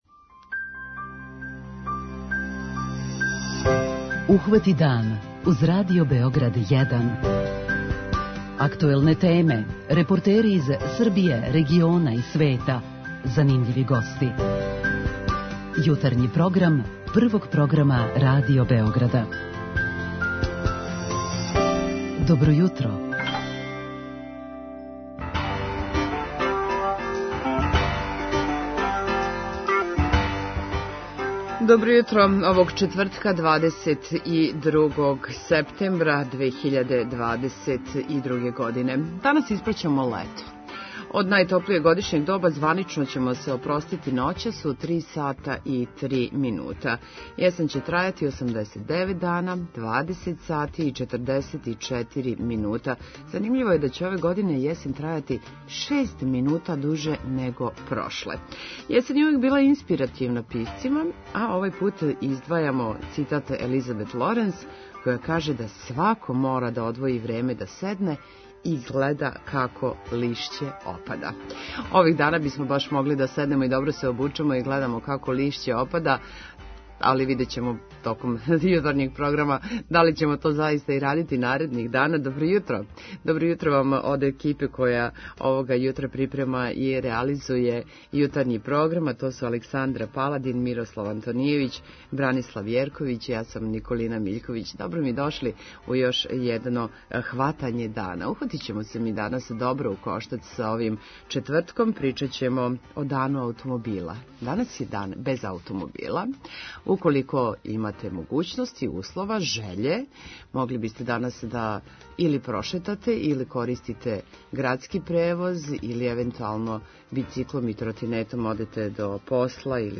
Чућемо и како деца гледају на дан без аутомобила и заштиту животне средине. ПИТАЊЕ ЈУТРА: Можемо ли без аутомобила?
преузми : 37.77 MB Ухвати дан Autor: Група аутора Јутарњи програм Радио Београда 1!